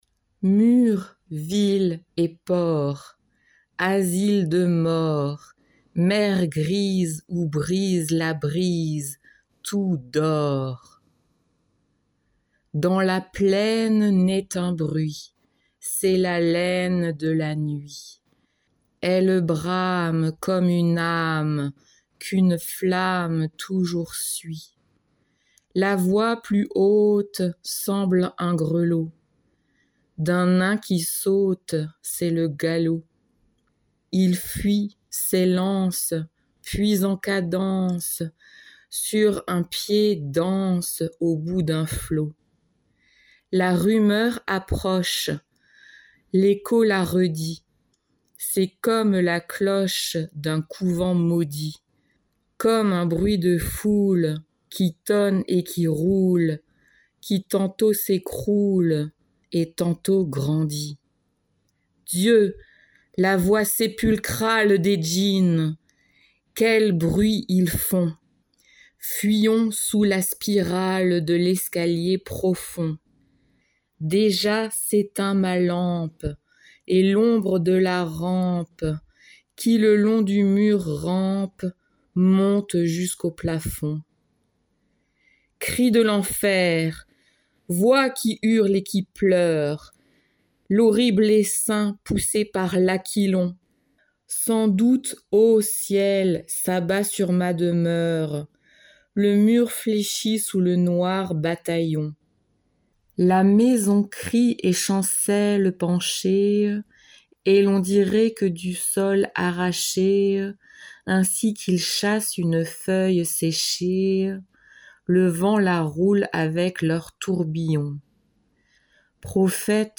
SATB (4 voices mixed) ; Full score.
Romantic.
Mood of the piece: fast
Instrumentation: Piano (1 instrumental part(s))
Tonality: F minor